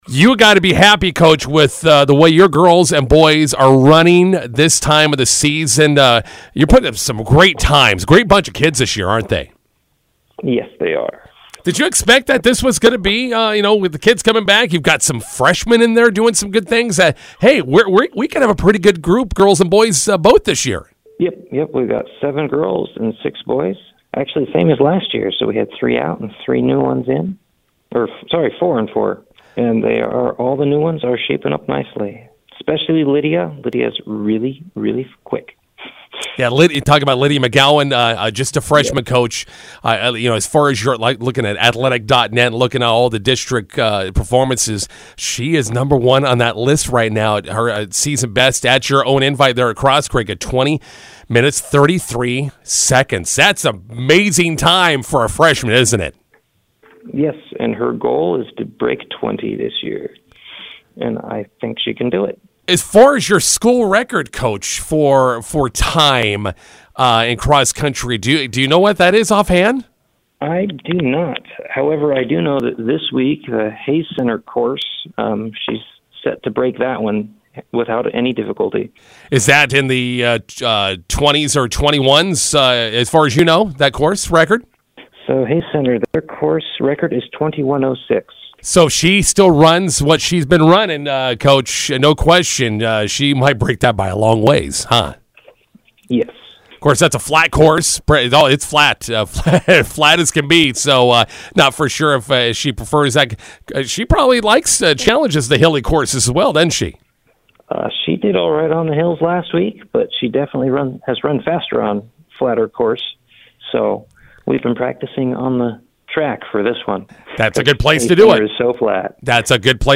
INTERVIEW: Cambridge CC prepares for MHC and UNK Invites.